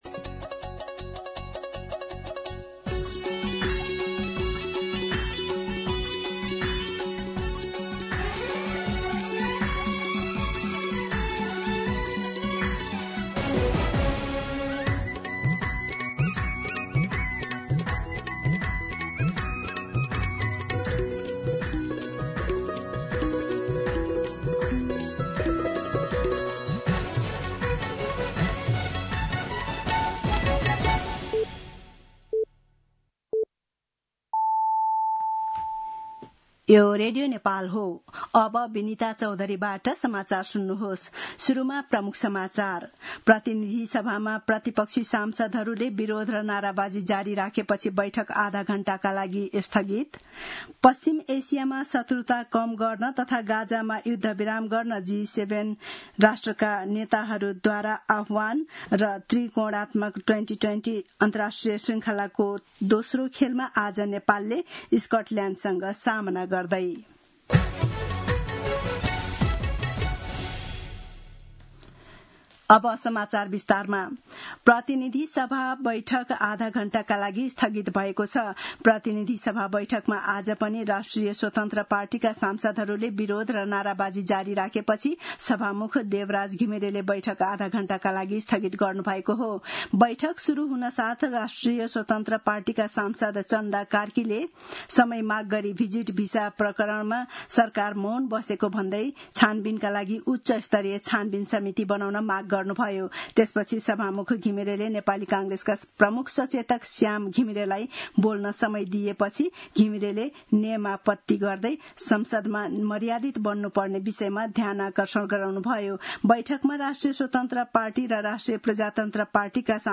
दिउँसो ३ बजेको नेपाली समाचार : ३ असार , २०८२